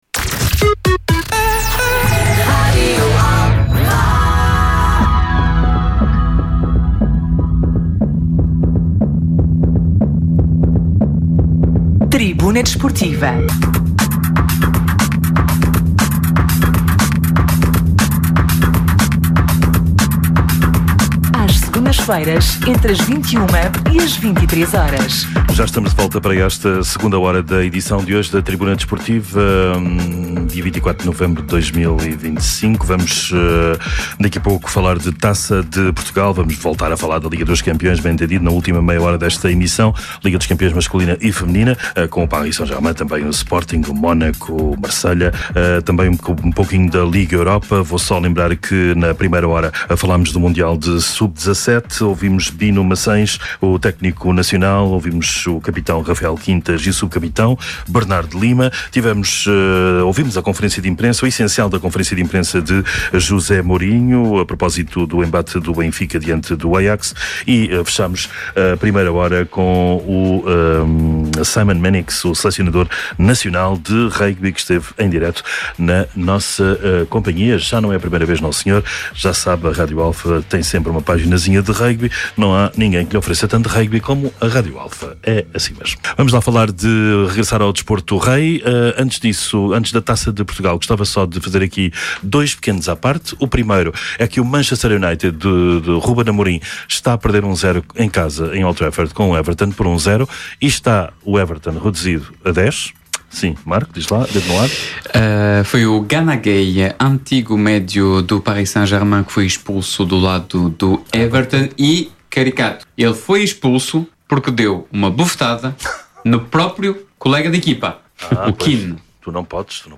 Atualidade Desportiva, Entrevistas, Comentários, Crónicas e Reportagens.
Tribuna Desportiva é um programa desportivo da Rádio Alfa às Segundas-feiras, entre as 21h e as 23h.